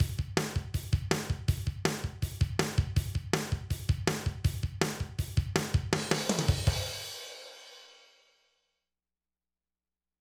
Boss Dr Rhythm DR-3 Sample Pack_Loop10.wav